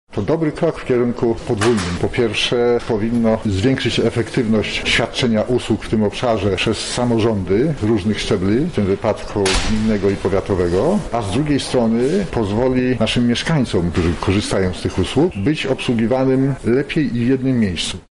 • mówi Starosta Lubelski Zdzisław Antoń.